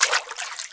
WATER 3.WAV